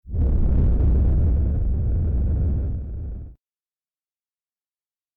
fire.mp3